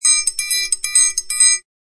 Ding-A-Ling.mp3